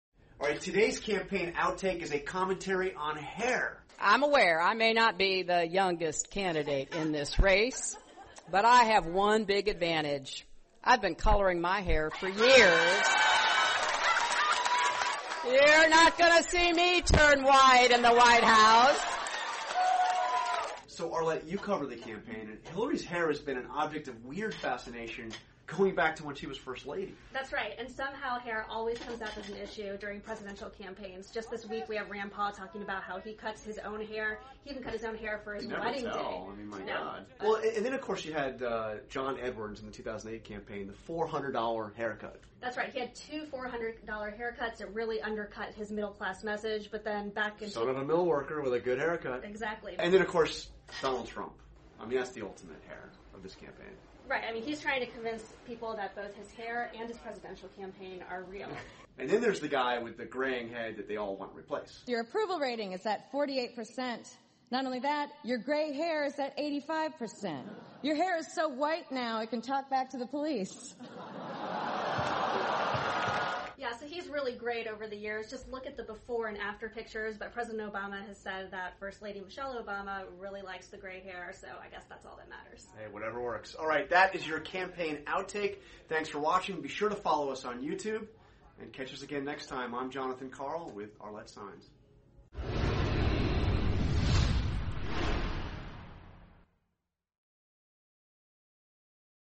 访谈录 希拉里·克林顿谈发型 听力文件下载—在线英语听力室